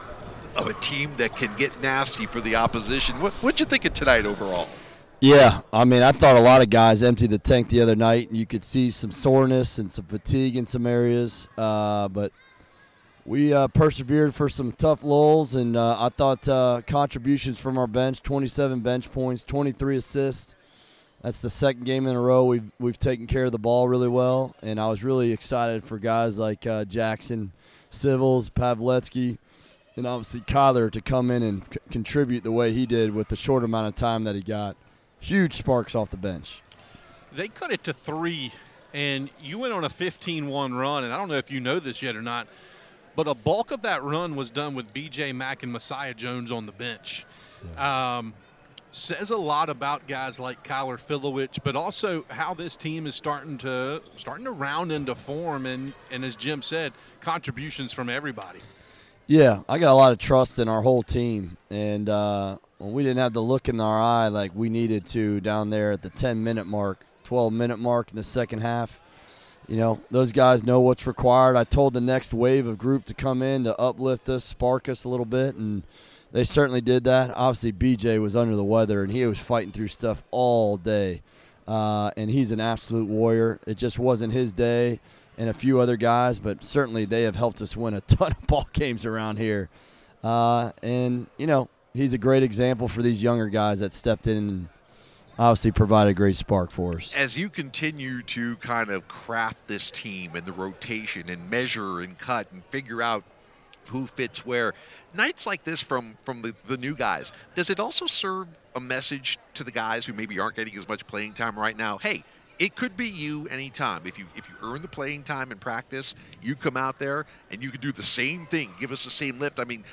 Postgame comments after NC A&T win. Download Listen Now All Categories Postgame Audio All Sports Men's Basketball Women's Basketball Loading More Podcasts...